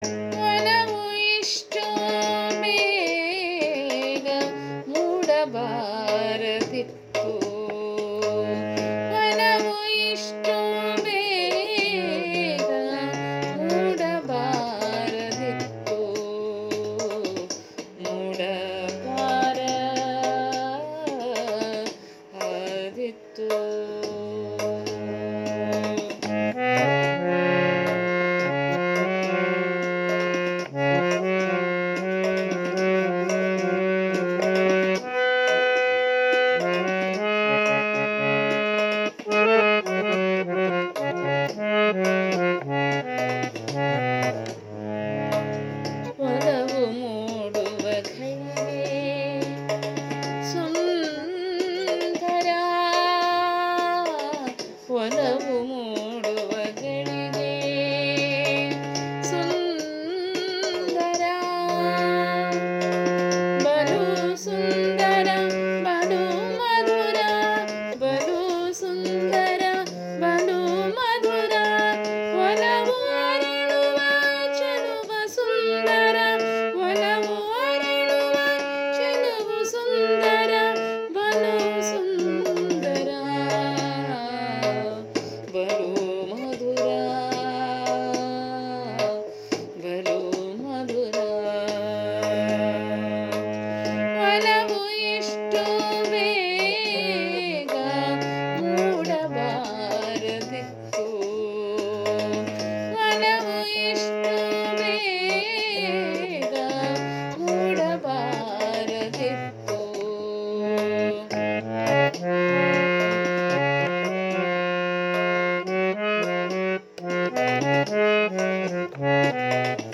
ರಾಗಸಂಯೋಜನೆ-ಗಾಯನ